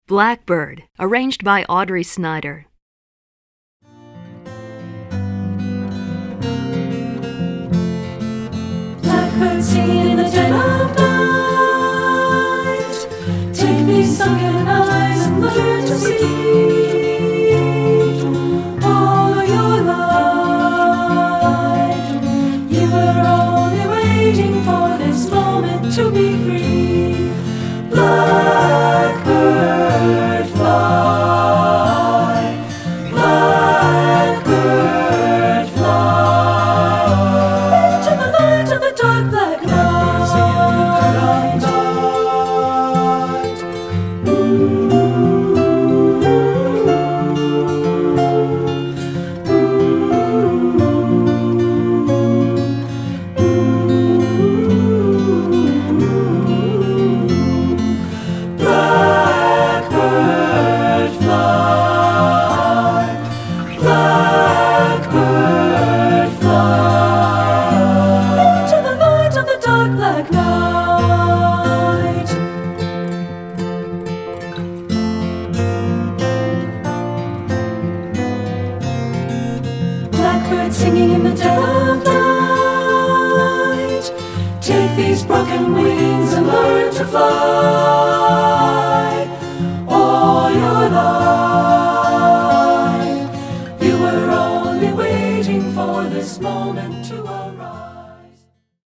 Choral 50's and 60's Pop